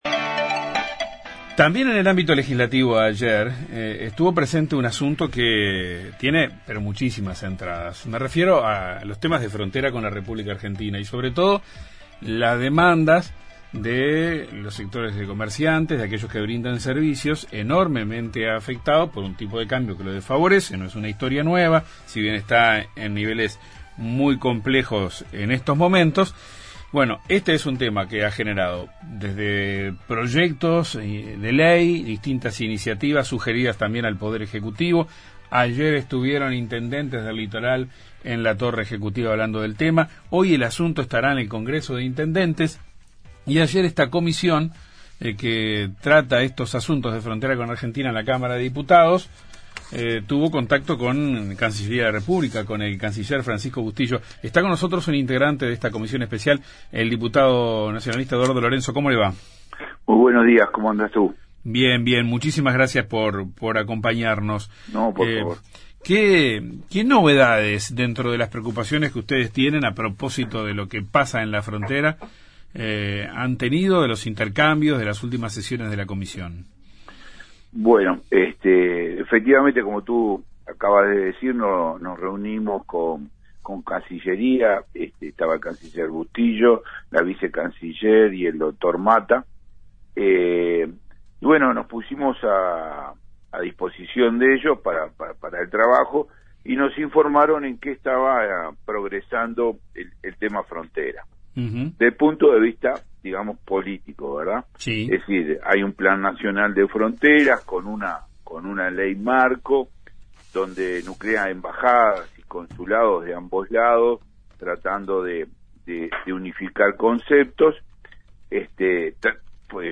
El diputado nacionalista por Río Negro Eduardo Lorenzo, integrante de ese grupo de trabajo, dijo a Informativo Uruguay esperan que el Poder Ejecutivo preste atención a las demandas.